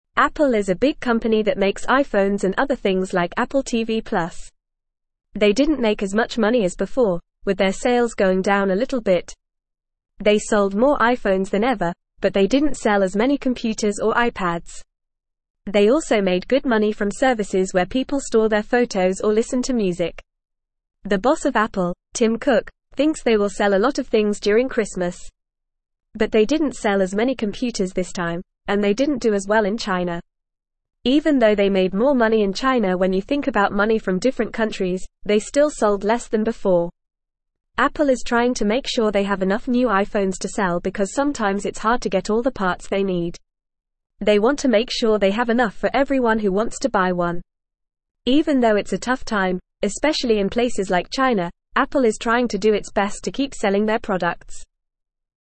Fast
English-Newsroom-Lower-Intermediate-FAST-Reading-Apple-Sells-Many-Things-But-Not-As-Many.mp3